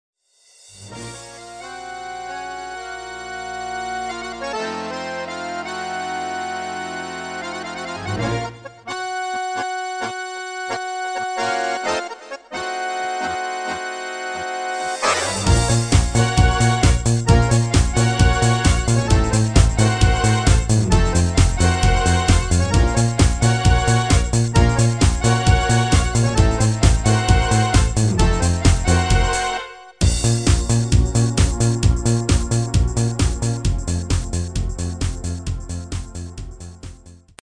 Demo's zijn eigen opnames van onze digitale arrangementen.